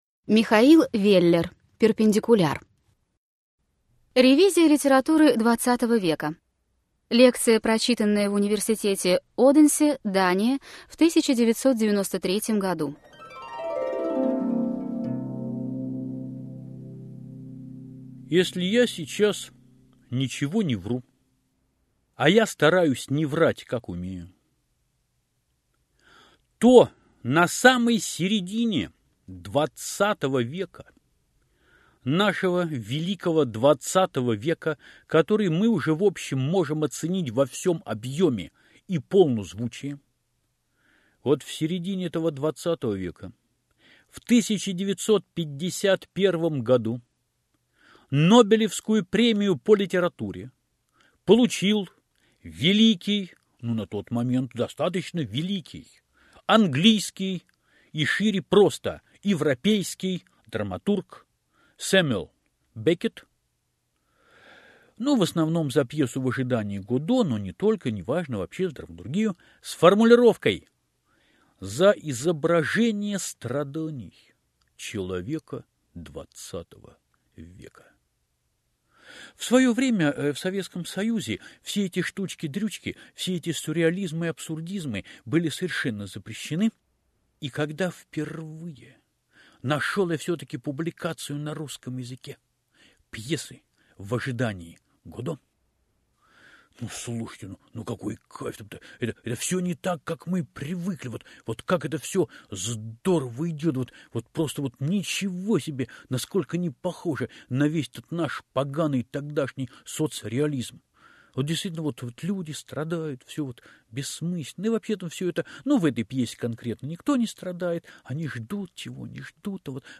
Аудиокнига Перпендикуляр | Библиотека аудиокниг
Aудиокнига Перпендикуляр Автор Михаил Веллер Читает аудиокнигу Михаил Веллер.